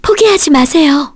Frey-Vox_Skill1_kr.wav